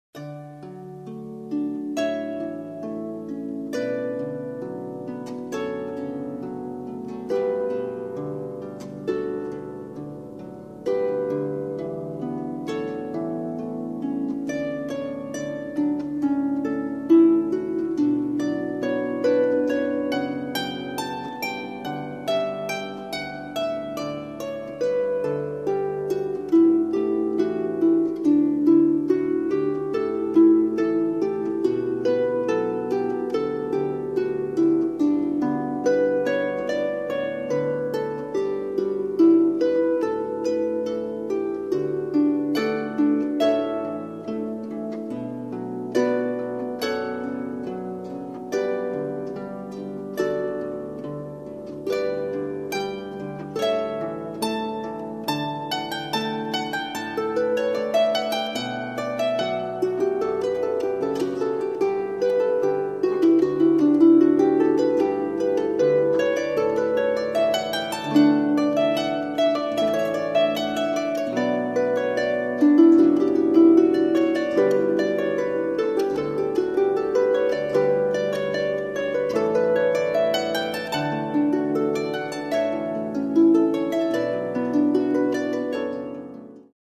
Wedding Harpist